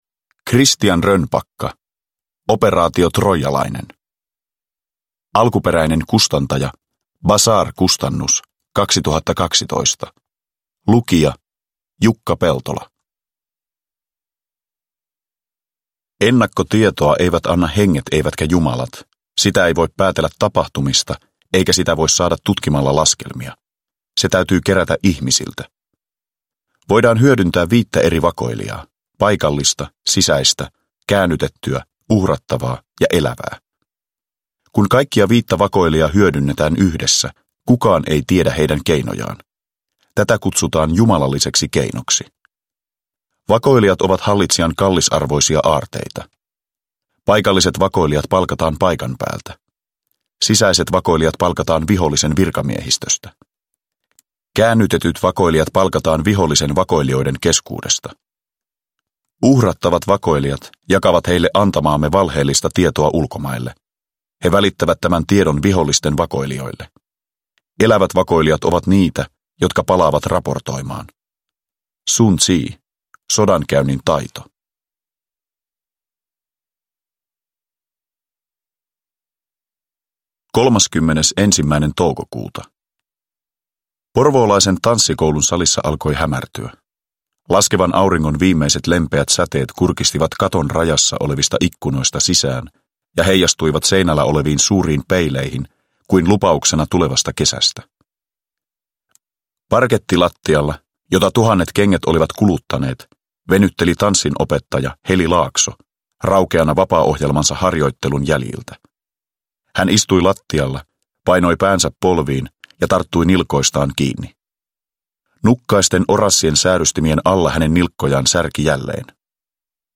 Operaatio Troijalainen – Ljudbok – Laddas ner
Uppläsare: Jukka Peltola